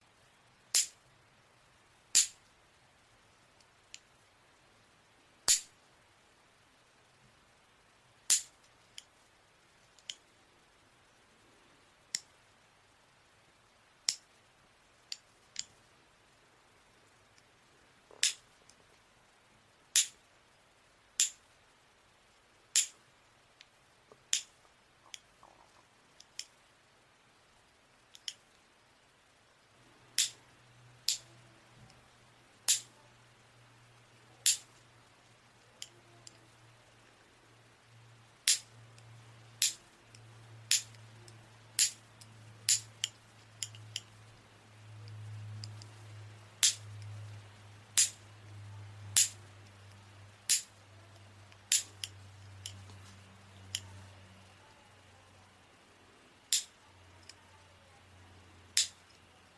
Tiếng Cắt, Bấm Móng Tay, làm nail…
Thể loại: Tiếng động
Description: Âm thanh cắt tỉa móng tay, tiếng kéo móng sắc bén, âm thanh bấm móng giòn tan, tiếng dũa móng nhẹ nhàng, sound effect làm nail chuyên nghiệp. Hiệu ứng âm thanh chân thực phục vụ cho việc edit video làm đẹp, clip hướng dẫn nail art, content spa và chăm sóc móng.
tieng-cat-bam-mong-tay-lam-nail-www_tiengdong_com.mp3